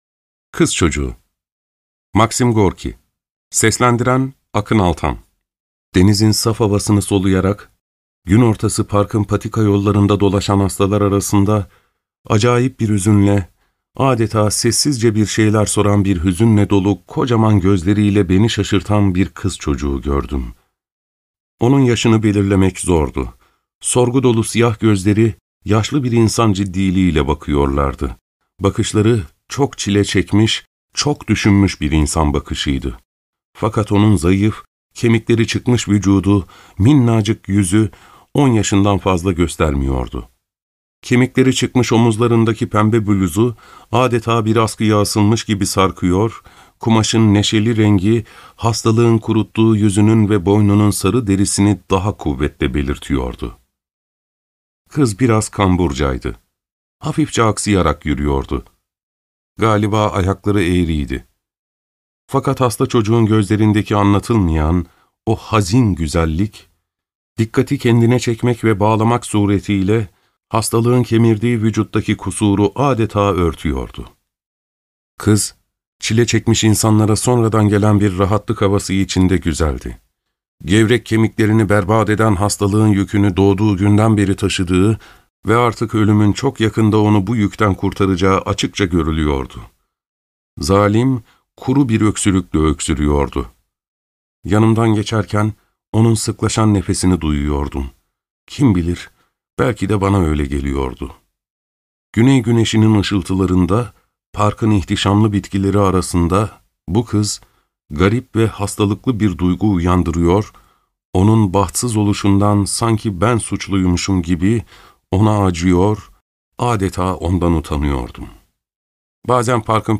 Neden Sesli Kitap Olarak Dinlemelisiniz?